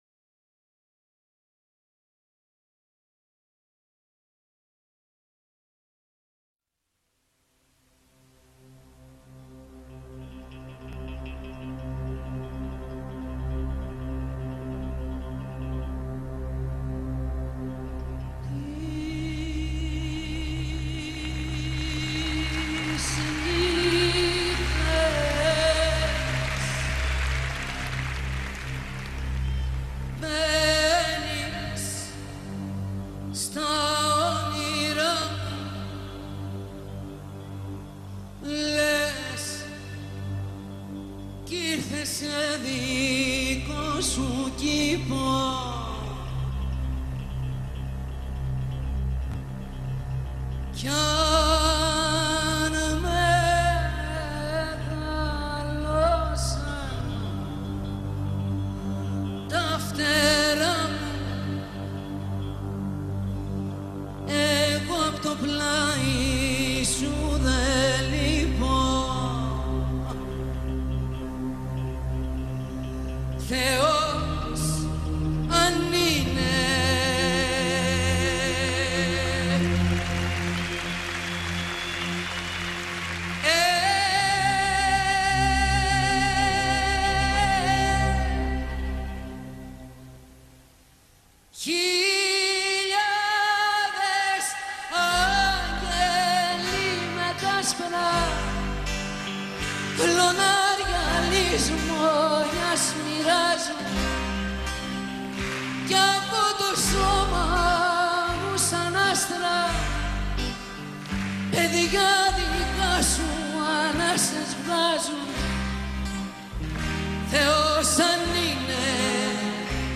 Bitte Geduld, nach ein paar Sekunden beginnt die Musik